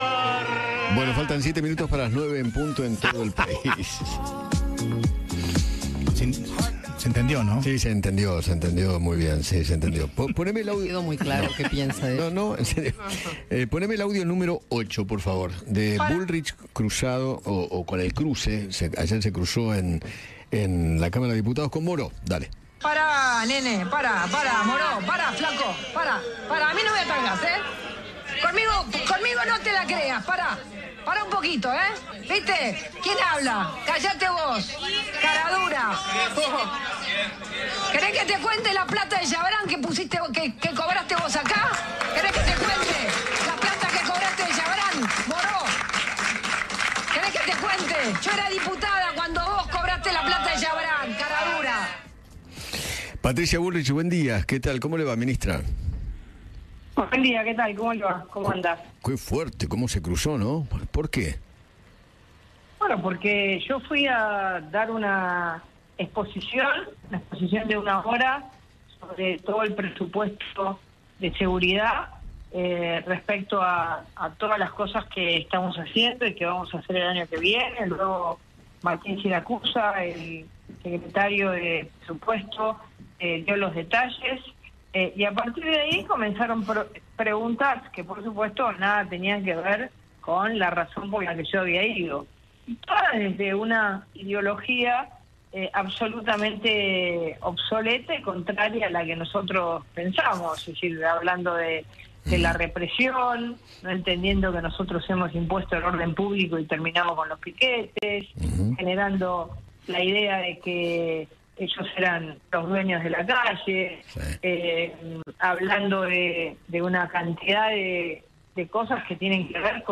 Eduardo Feinmann habló con la ministra de Seguridad, Patricia Bullrich, sobre las tomas en las universidades y se refirio al Presupuesto 2025 en materia de seguridad.